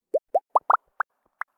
Soap bubbles pop
balloon bubbles pop soap sound effect free sound royalty free Sound Effects